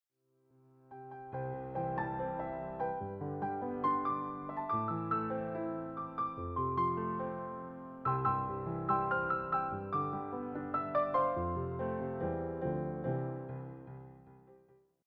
all reimagined as solo piano pieces.
just the piano, no vocals, no band.